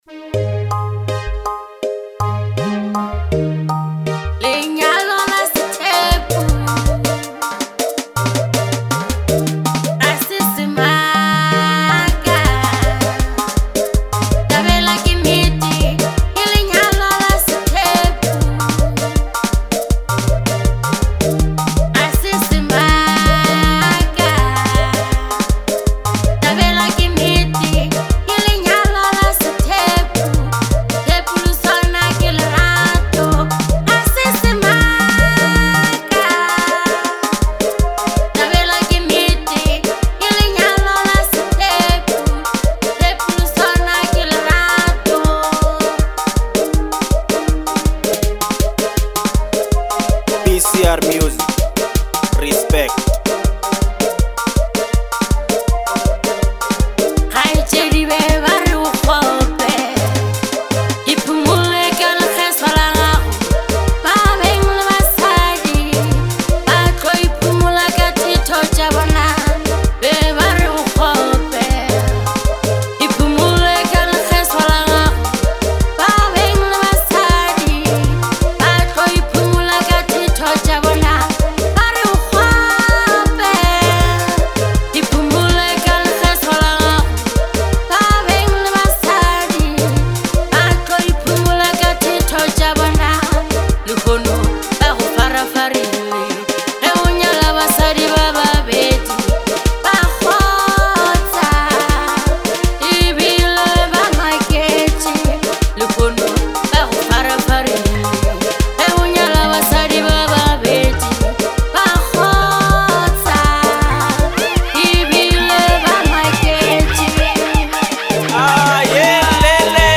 Manyalo